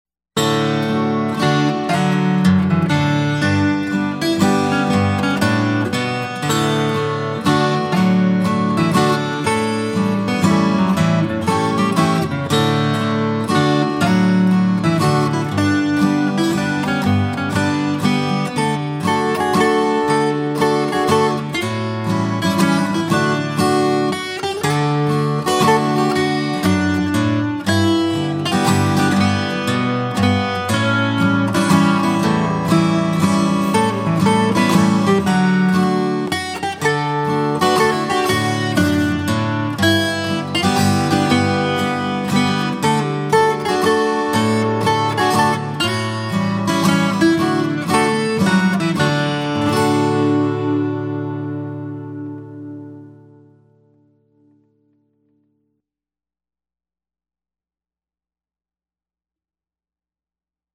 DIGITAL SHEET MUSIC - FLATPICK/PLECTRUM GUITAR SOLO
FLATPICK/PLECTRUM GUITAR SOLO Waltz